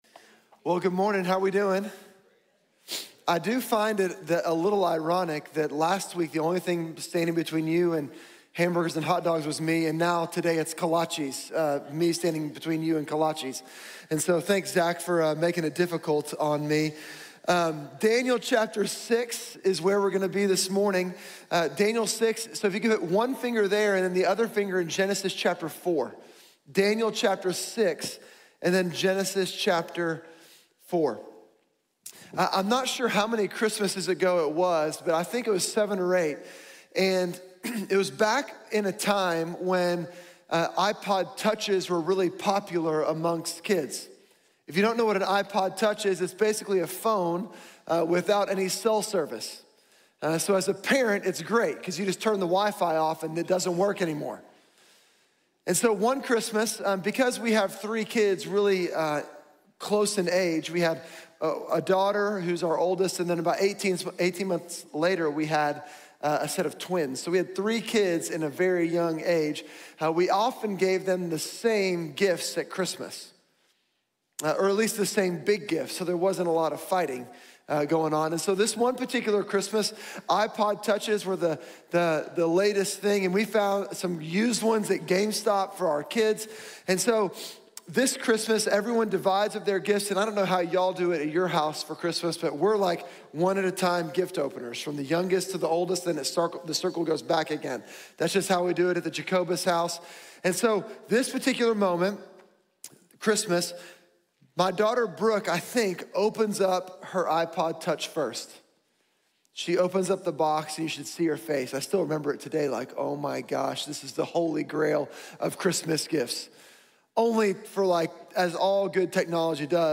Cultivando el contentamiento | Sermón | Iglesia Bíblica de la Gracia